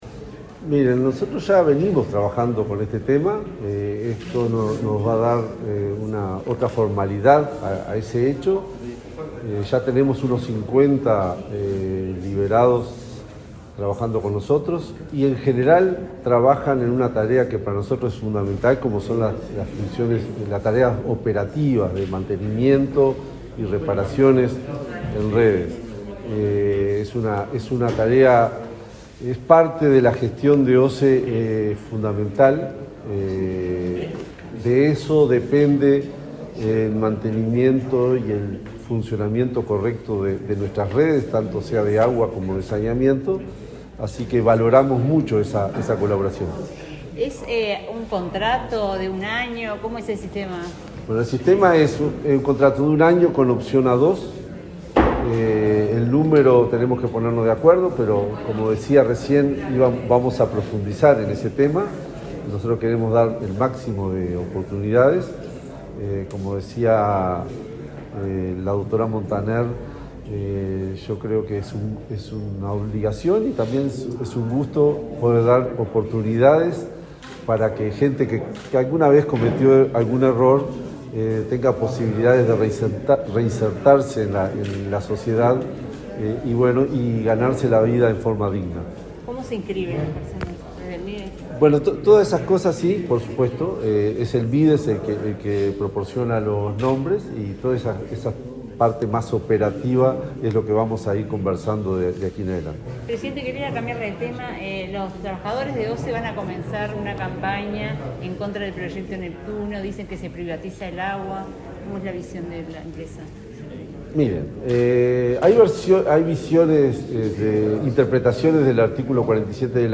Declaraciones del presidente de OSE a la prensa
Declaraciones del presidente de OSE a la prensa 27/05/2022 Compartir Facebook X Copiar enlace WhatsApp LinkedIn Este viernes 27, el Ministerio de Desarrollo Social (Mides), a través de la Dirección Nacional del Liberado (Dinali), firmó un convenio con OSE, para otorgar pasantías de trabajo a personas que han egresado del sistema carcelario. Luego, el presidente de la empresa pública, Raúl Montero, dialogó con la prensa.